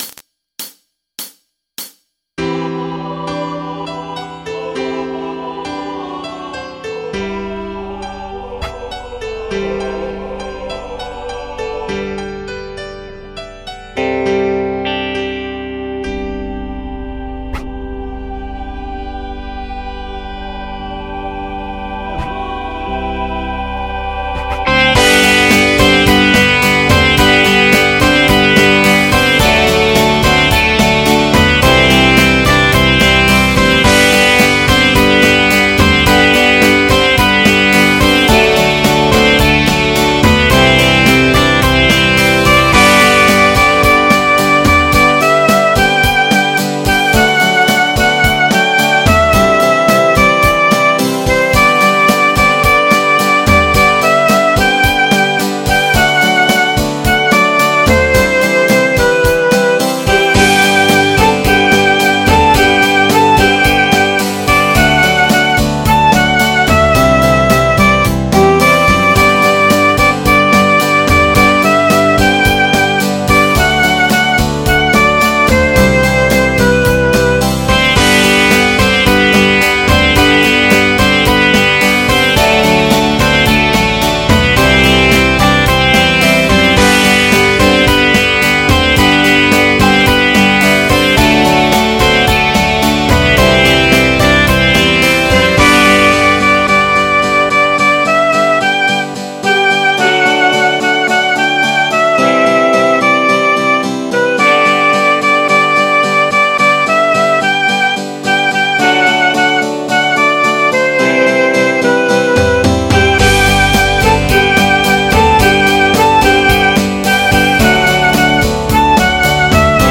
MIDI 60.74 KB MP3 (Converted) 4.05 MB MIDI-XML Sheet Music